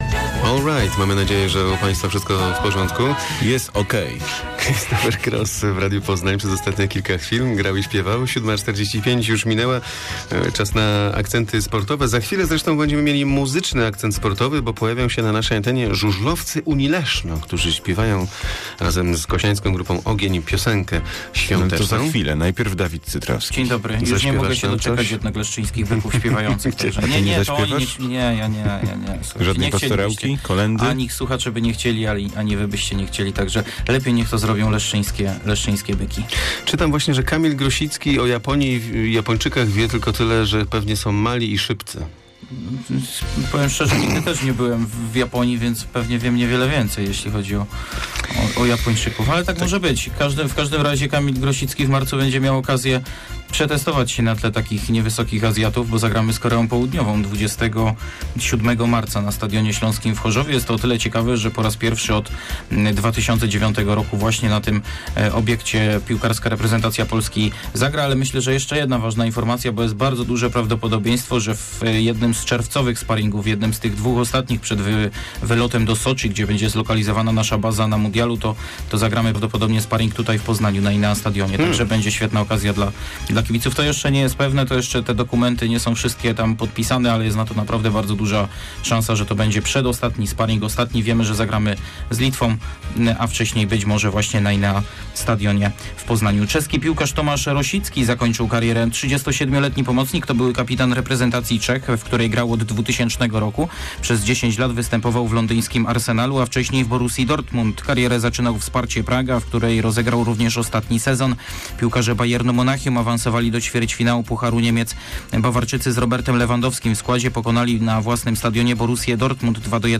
21.12 serwis sportowy godz. 7:45